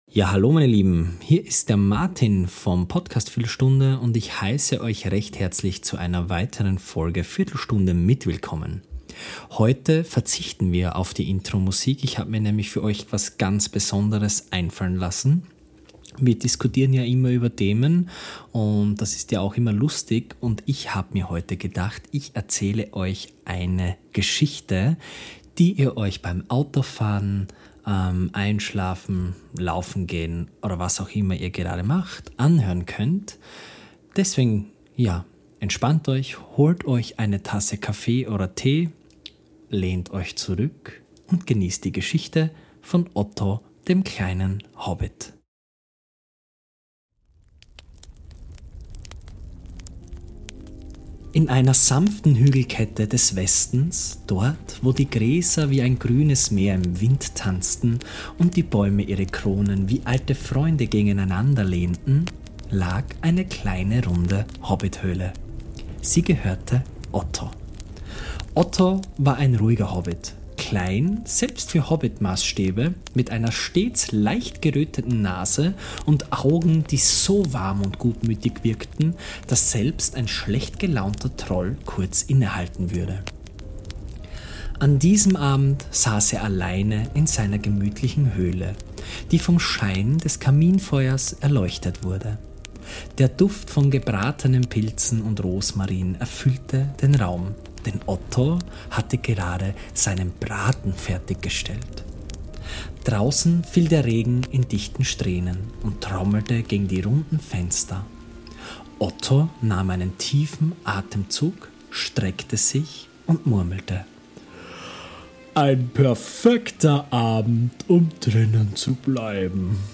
Eine gemütliche Geschichte